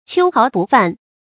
秋毫不犯 注音： ㄑㄧㄡ ㄏㄠˊ ㄅㄨˋ ㄈㄢˋ 讀音讀法： 意思解釋： 秋毫：鳥獸秋天新換的絨毛，比喻極細微的東西；犯：侵犯。